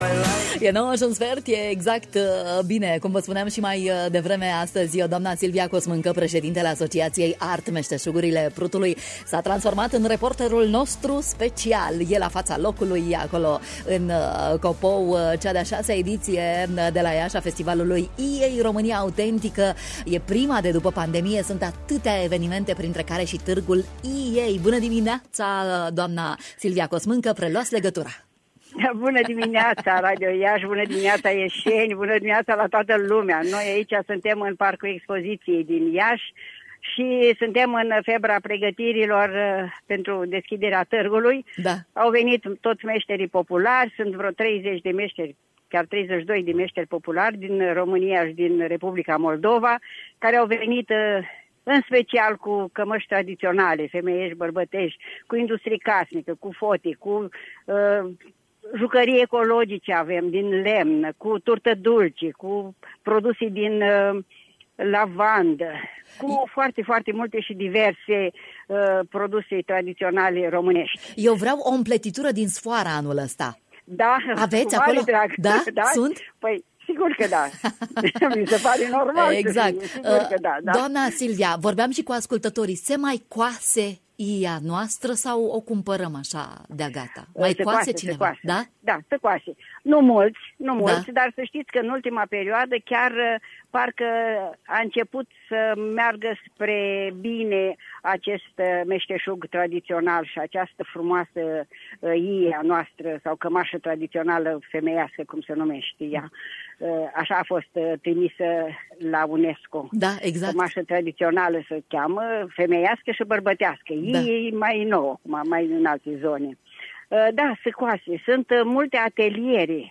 Târgul Meşteşugarilor şi alte evenimente. În direct de la festival